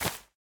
sounds / block / vine / break3.ogg
break3.ogg